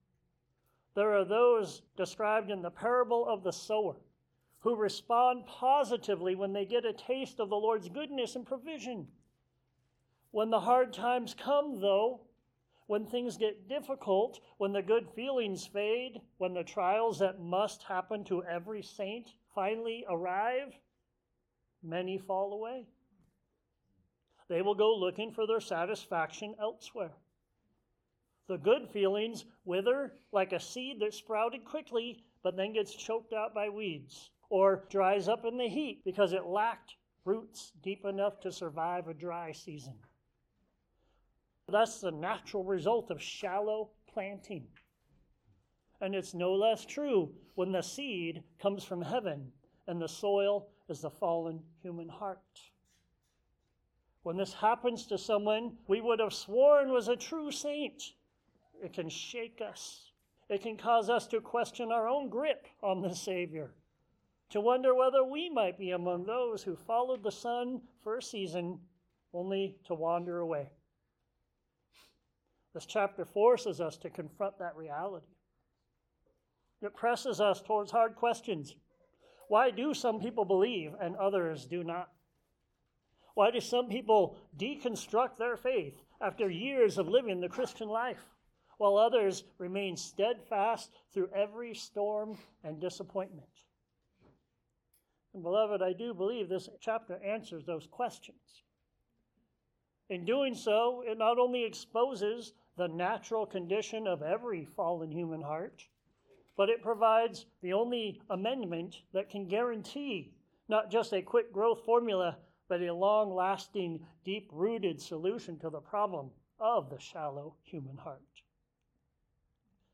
The Grumblers John 6:25-46 Sermons Share this: Share on X (Opens in new window) X Share on Facebook (Opens in new window) Facebook Like Loading...